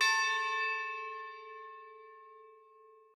bell1_6.ogg